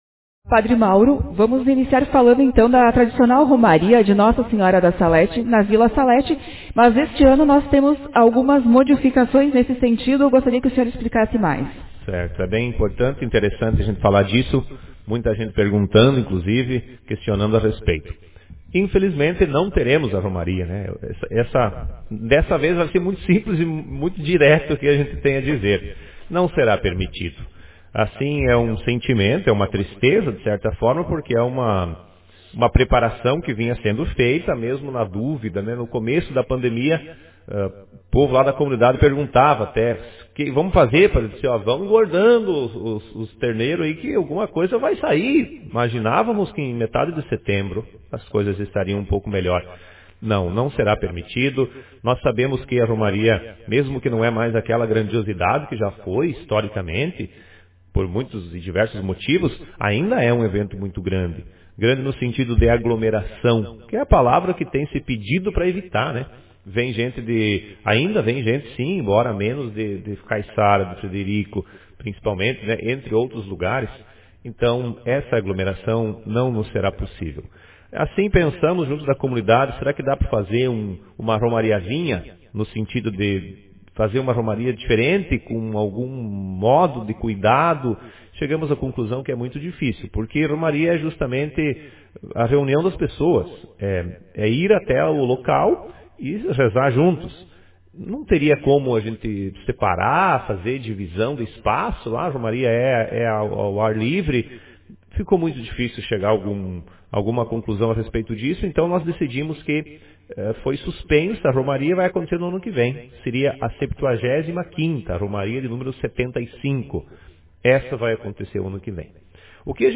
Manchete